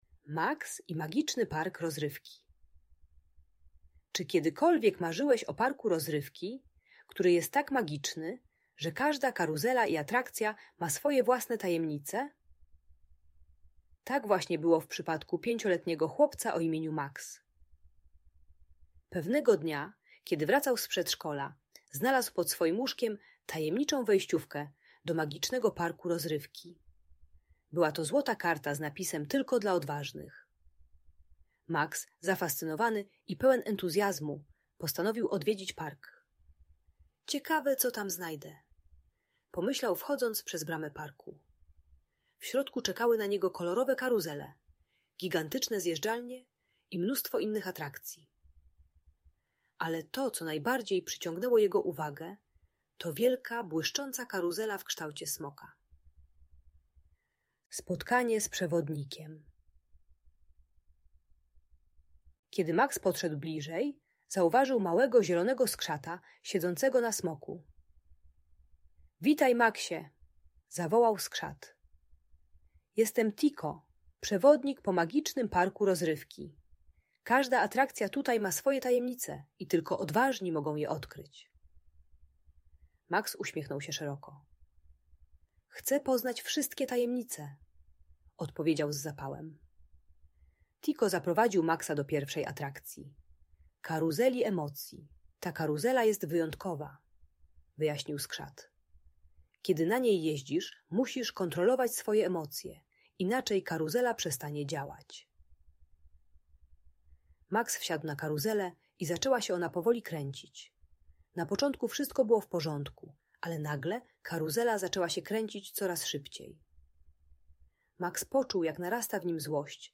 Maks i Magiczny Park Rozrywki - Audiobajka dla dzieci